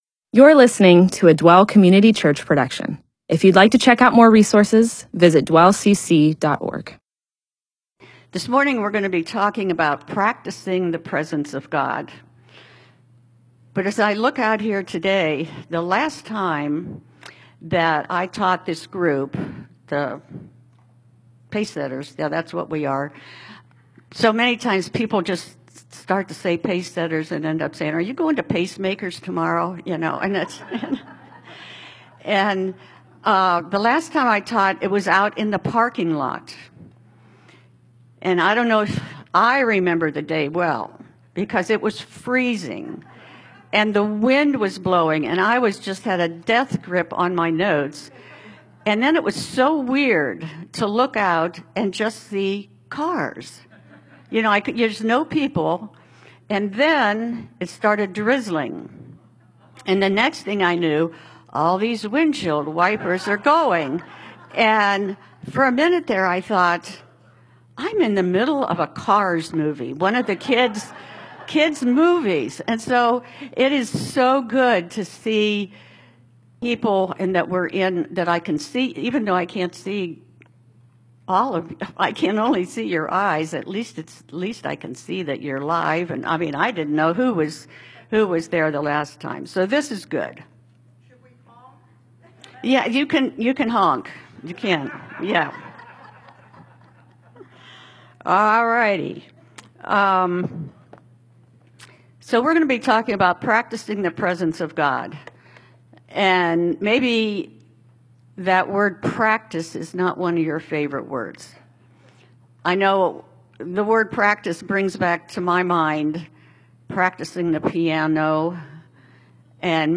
Bible teaching (presentation, sermon) on Isaiah 26:3, Title: Practicing the Presence of God, Date: 12/30/2021